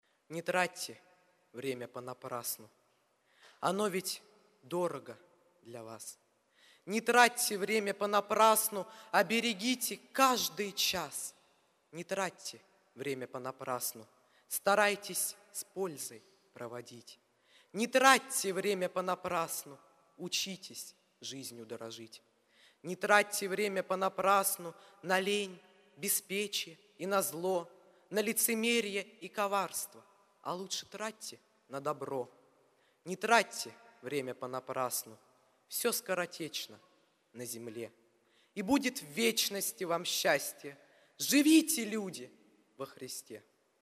Богослужение 25.08.2024
Стихотворение